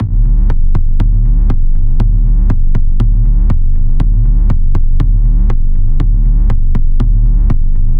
描述：flstudio随机行动
标签： 实验 毛刺 踢毛刺环
声道立体声